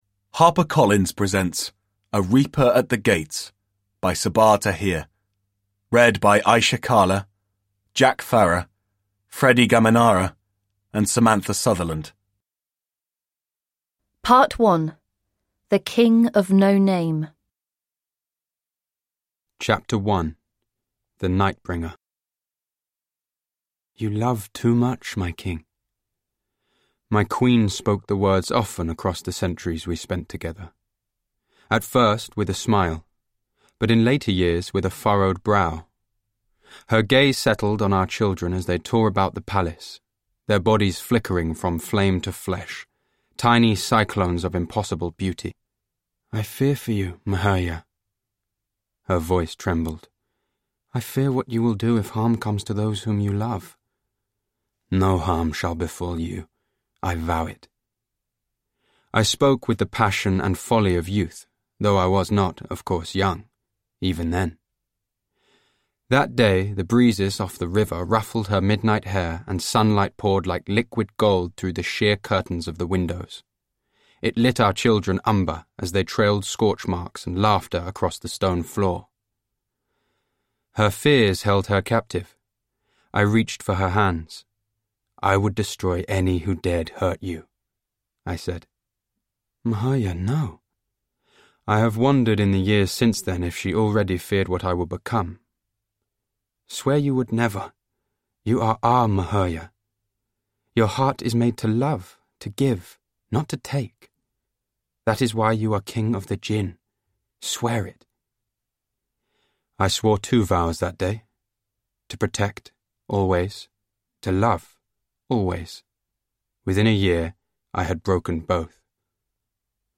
A Reaper at the Gates – Ljudbok